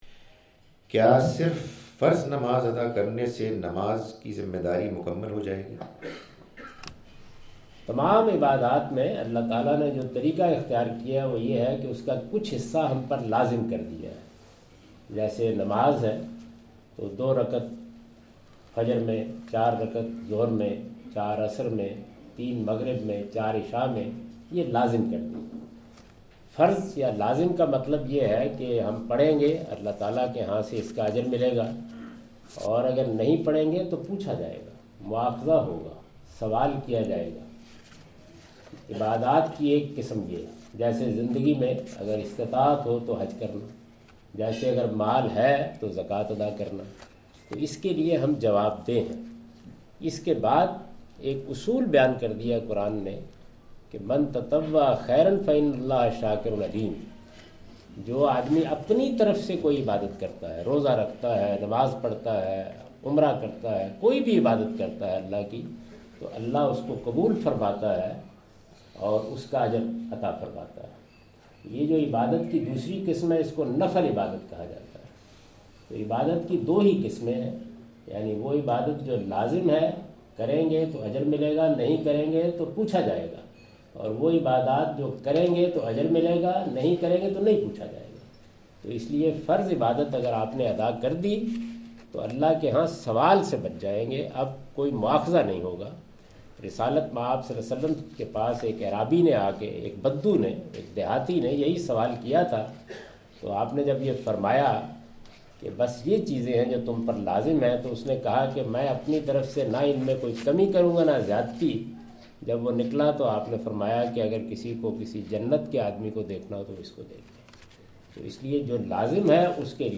Javed Ahmad Ghamidi answer the question about "offering obligatory rakahs of prayer only" during his visit to Manchester UK in March 06, 2016.
جاوید احمد صاحب غامدی اپنے دورہ برطانیہ 2016 کے دوران مانچسٹر میں "کیا صرف فرض رکعات ادا کرنا کافی ہے؟" سے متعلق ایک سوال کا جواب دے رہے ہیں۔